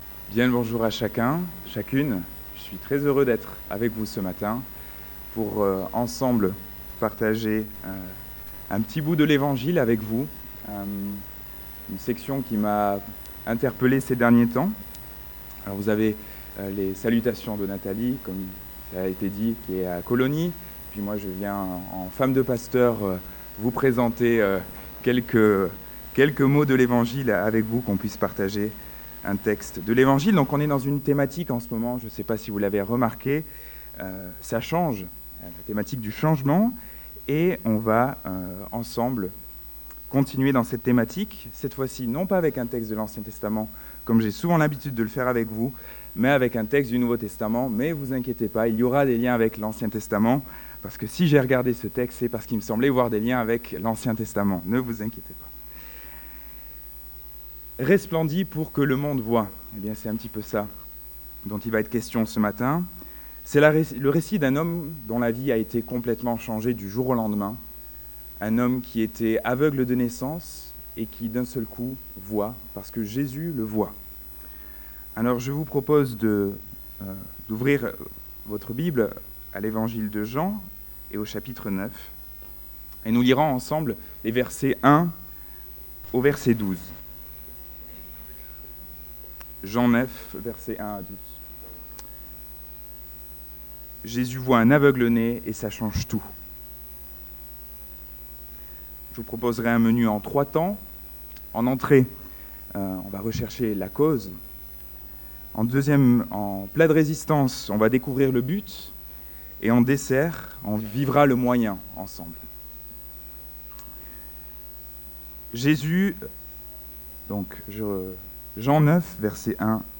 Culte du 15 octobre 2017